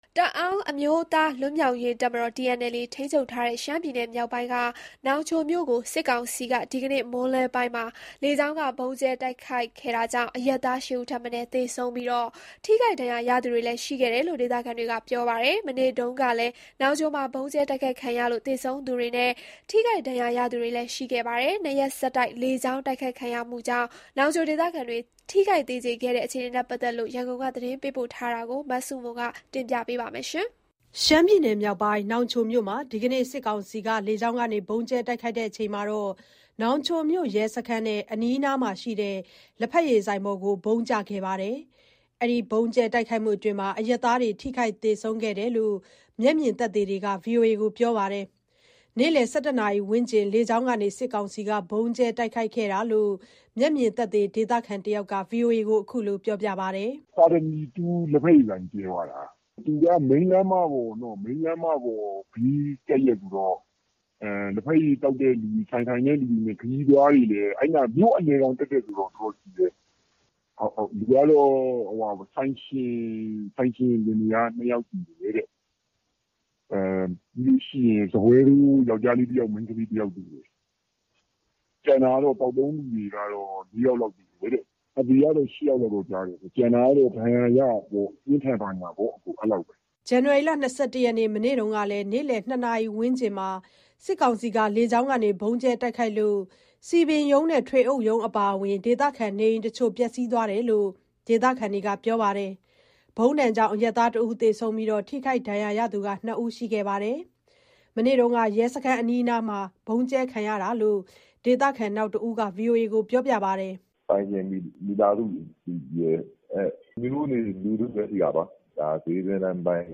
တအောင်းအမျိုးသား လွတ်မြောက်ရေးတပ်မတော် TNLA ထိန်းချုပ်ထားတဲ့ ရှမ်းပြည်နယ်မြောက်ပိုင်းက နောင်ချိုမြို့ကို စစ်ကောင်စီက ဒီနေ့မွန်းလွဲပိုင်းမှာ လေကြောင်းက ဗုံးကြဲတိုက်ခိုက်ခဲ့တာကြောင့် အရပ်သား ၈ ဦးထက်မနည်း သေဆုံးပြီး၊ ထိခိုက်ဒဏ်ရာရသူတွေလည်း ရှိခဲ့တယ်လို့ ဒေသခံတွေက ပြောပါတယ်။ မနေ့တုန်းကလည်း နောင်ချိုမှာ ဗုံးကြဲတိုက်ခိုက်ခံရလို့ သေဆုံးသူနဲ့ ထိခိုက်ဒဏ်ရာရသူတွေ ရှိခဲ့ပါတယ်။ နောင်ချိုမြို့မှာ ၂ ရက်ဆက် လေကြောင်းတိုက်ခိုက်ခံရတဲ့ အခြေအနေ ရန်ကုန်ကနေ သတင်းပေးပို့ထားပါတယ်။